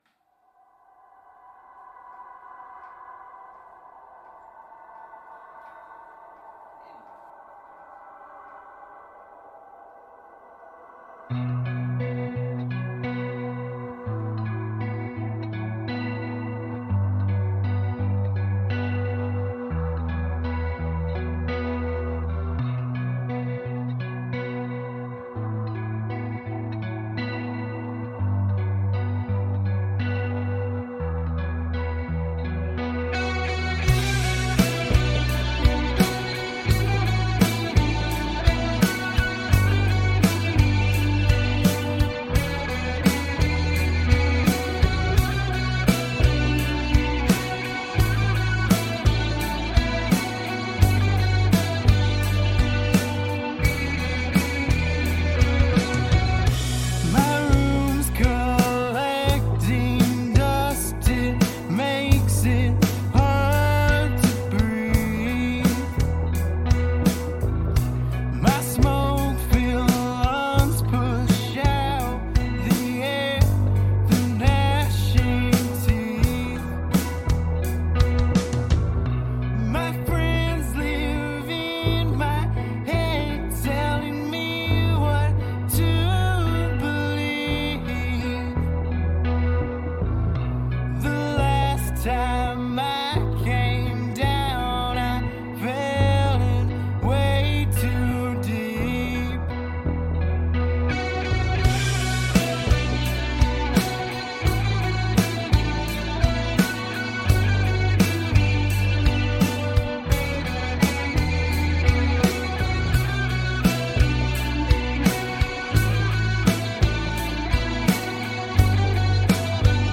blending 90s rock and country influences